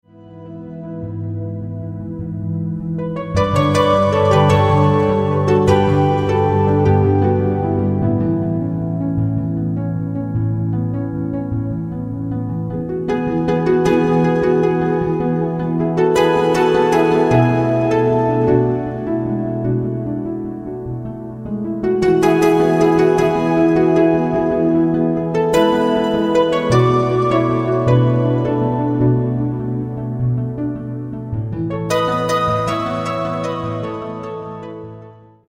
spontaneous worship with vocals and flowing keyboard.